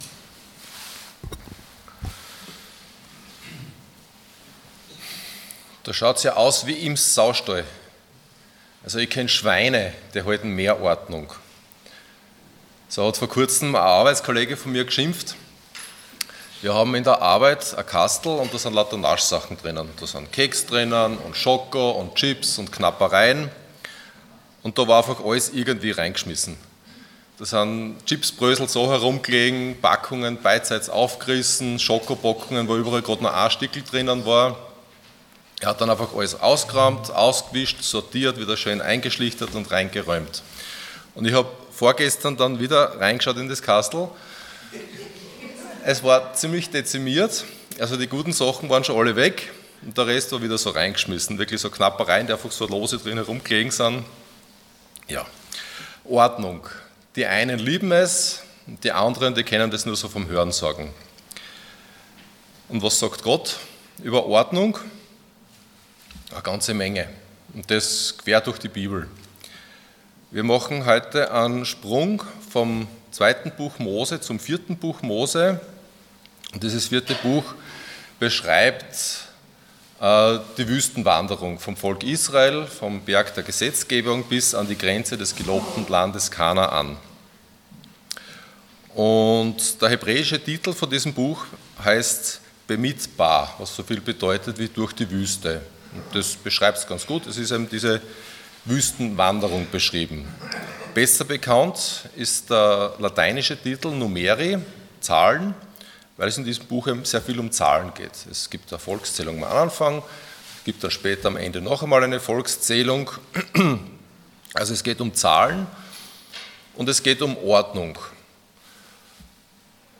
Passage: 4. Mose 9, 4. Mose 10:1-13 Dienstart: Sonntag Morgen Alles zur Ehre und Herrlichkeit Gottes Themen: Ehre , Herrlichkeit , Leben « Nach dem Super-GAU, kann es weitergehen?